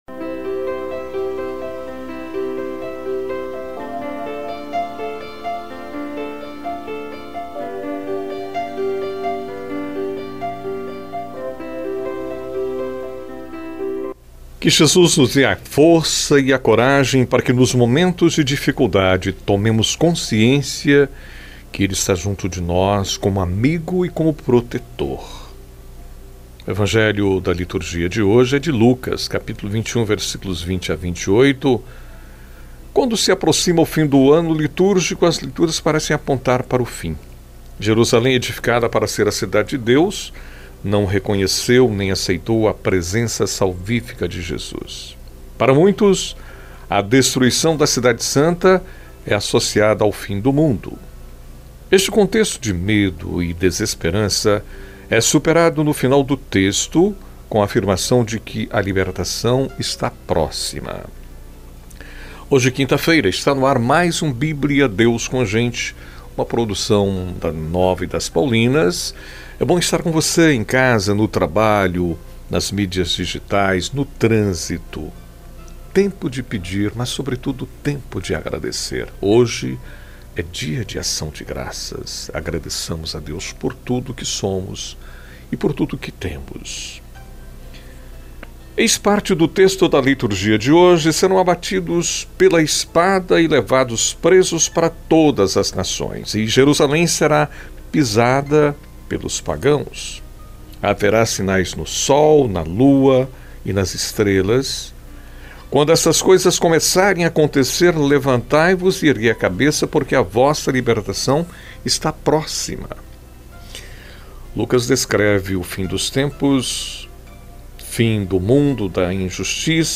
É um momento de reflexão diário com duração de aproximadamente 5 minutos, refletindo o evangelho do dia, indo ao ar de segunda a sexta